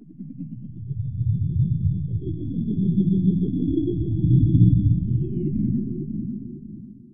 monolith_idle.ogg